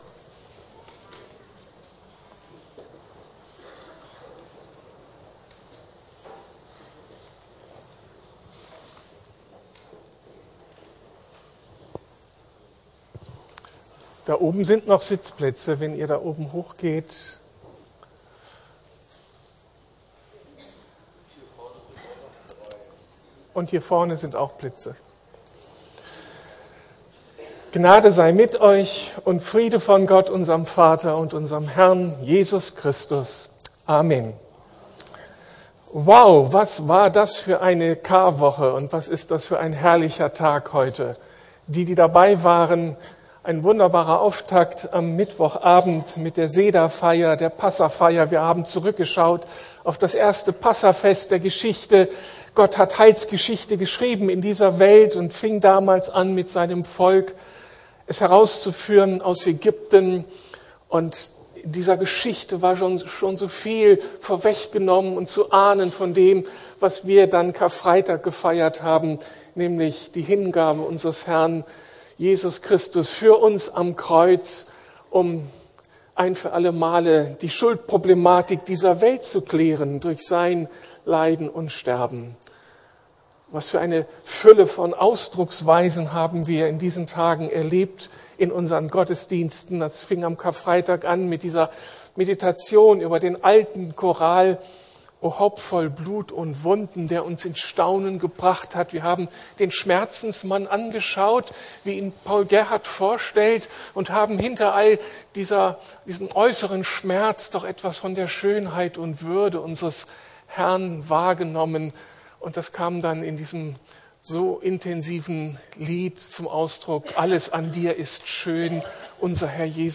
Die Auferstehungskräfte glaubend ergreifen lernen! ~ Predigten der LUKAS GEMEINDE Podcast